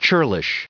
794_churlish.ogg